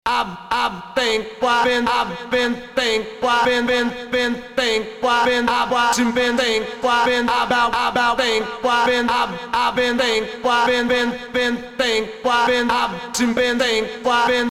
Категория: Скачать Зарубежные акапеллы